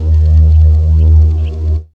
3706R SUBHUM.wav